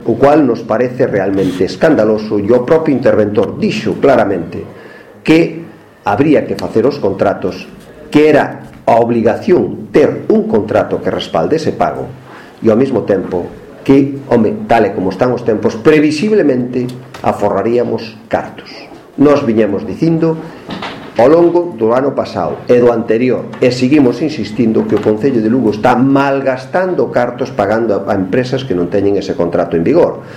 O voceiro do Grupo Municipal do Partido Popular, Jaime Castiñeira, denunciou esta mañá en rolda de prensa pagos do Concello en 2014 por importe de case 10 millóns de euros a un total de 10 empresas privadas sen contrato en vigor, tal como pon de manifesto o informe de Intervención que acompaña á Conta Xeral de 2014 e que foi presentado na Comisión Especial de Contas desta mañá previa ao pleno extraordinario do vindeiro martes 13 de outubro.